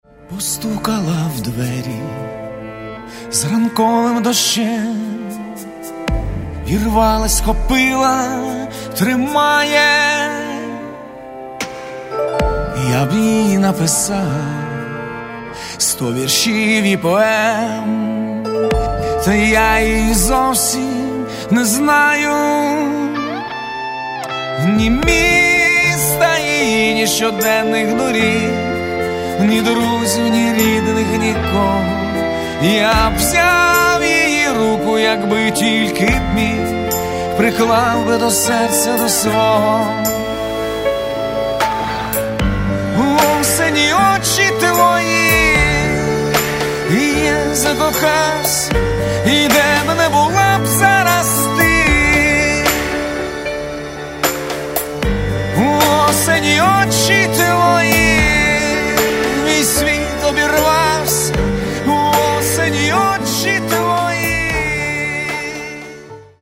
Каталог -> Рок и альтернатива -> Поэтический рок
альбом баллад, различных по динамике